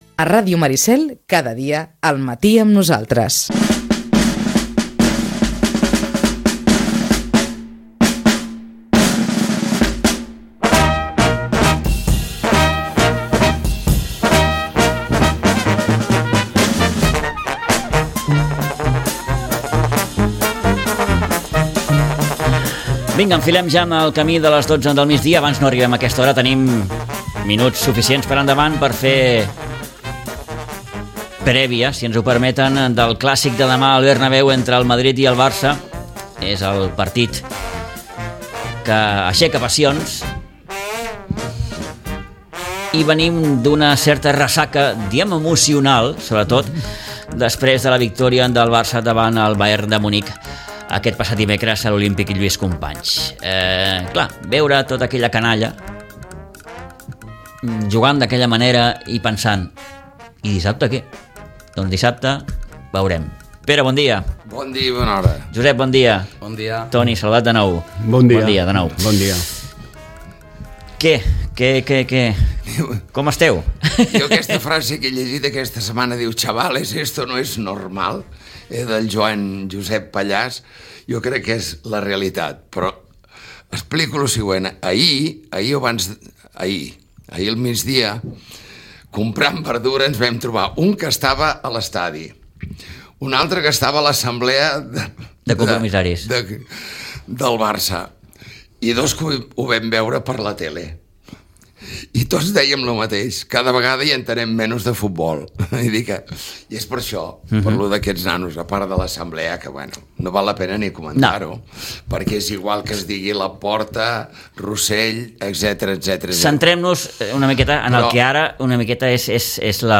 La tertúlia esportiva